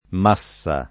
m#SSa] s. f. — sim. (dal sign. mediev. di «grande possesso») il top. M., in molti casi completato da un’aggiunta, di solito staccata nella grafia: Massa Macinaia [m#SSa ma©in#La] (Tosc.), Massa Marittima [m#SSa mar&ttima] (id.), Massa Martana [